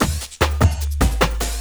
50 LOOP09 -L.wav